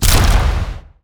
8 bits Elements
Weapons Demo
rocket_launcher2.wav